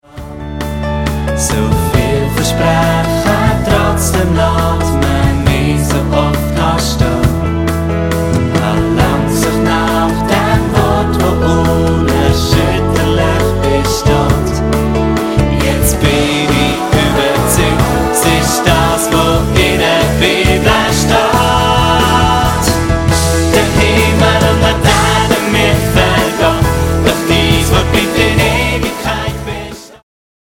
Mundart-Worshipsongs